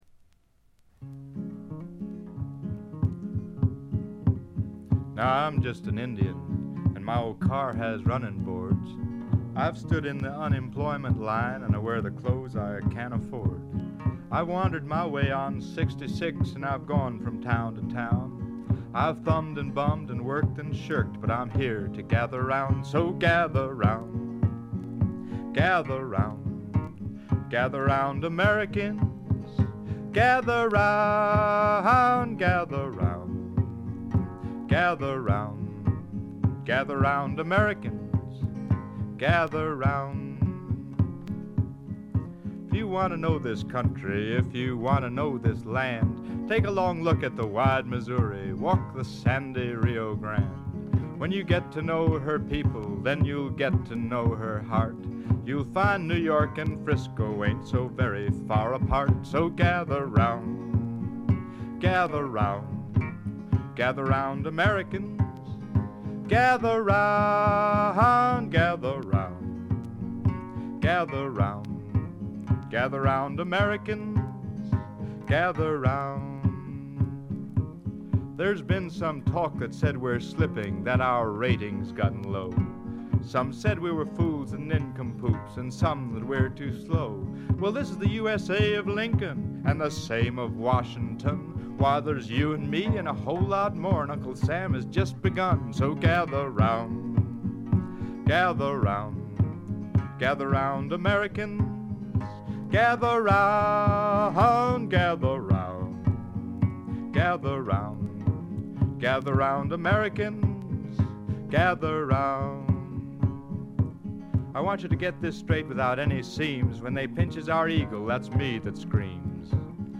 プレス起因と思われますが、ところどころでチリプチ。散発的なプツ音少し。
ジャケットからして異様な存在感を放っていますが、音の方も独特のノリがあって一種呪術的なすごい迫力です！
試聴曲は現品からの取り込み音源です。
Guitar, Vocals
Indian Drums